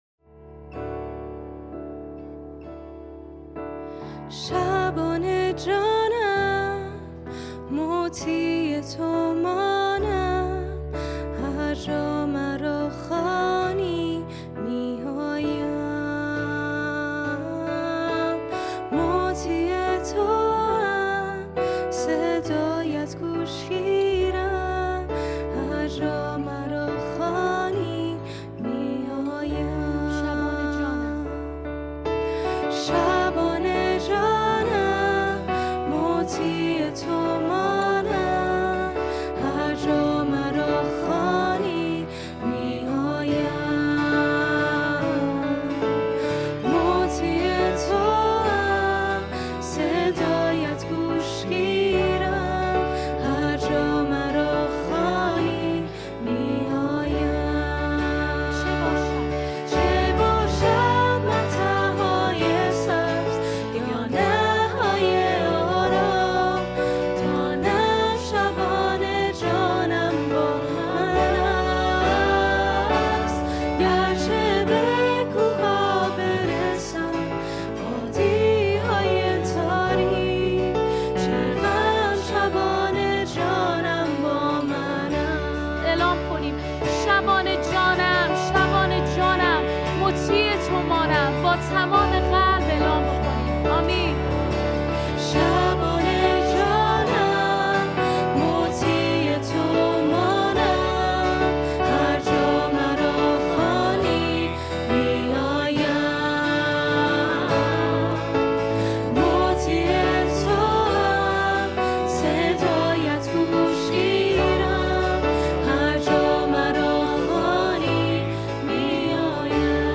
ماژور